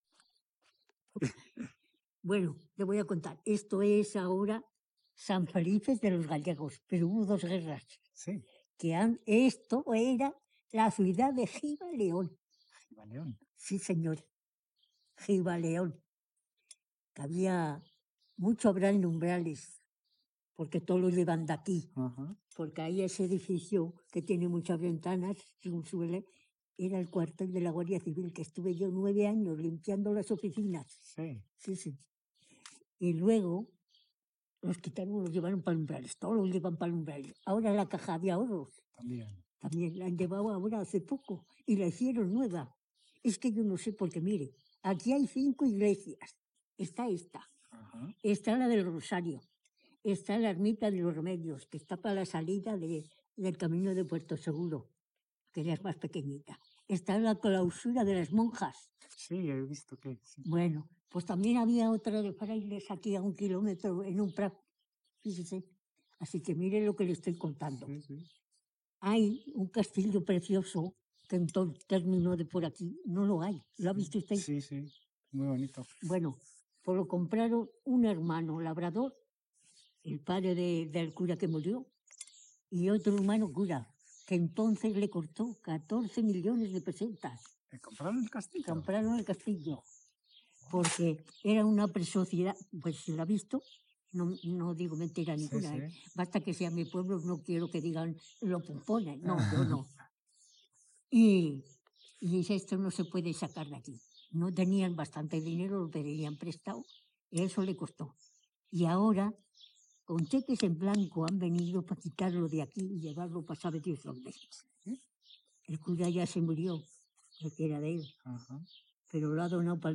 Enclave San Felices de los Gallegos (El Abadengo)
Encuesta
Informantes I1:�mujer